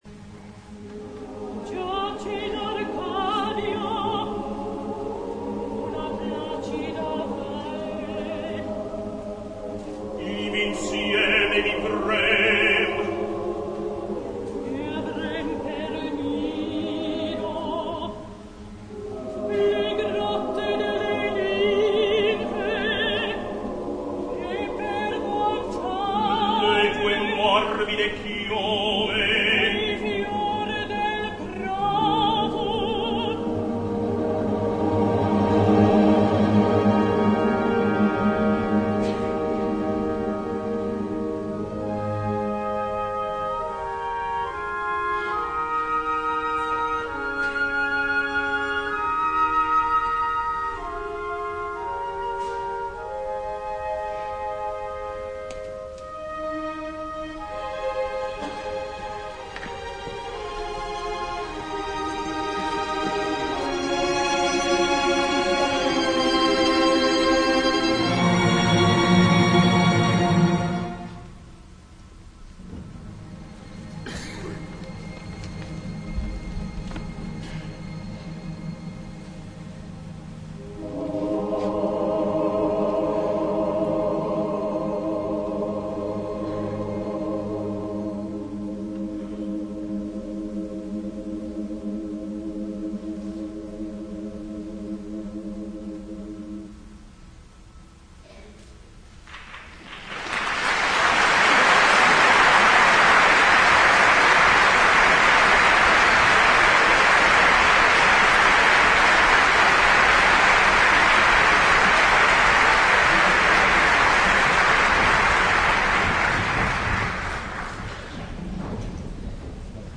Libreto y música de Arrigo Boito.
Pero a pesar de su origen germánico y al gran sinfonismo que despliega la partitura ya desde el mismo Prólogo en el Cielo, MEFISTOFELE permanece como un claro testimonio del lirismo italiano y de las líneas vocales melódicas y comprensibles.
En ésta ocasión vamos a tener el placer de escuchar en el papel protagónico al gran bajo estadounidense SAMUEL RAMEY, quien ha hecho una especialidad absoluta del papel protagónico en su repertorio operístico. También destacamos la presencia del tenor canadiense RICHARD MARGISON, en ese momento en el pináculo de su carrera artística, cantante de una voz ígnea y penetrante y con gran facilidad para la emisión de las notas agudas. Y tampoco pasamos por alto la excelente MARGHERITA de la Soprano italiana DANIELA DESSI, una excelente voz que fatídicamente perdiéramos a temprana edad.